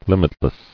[lim·it·less]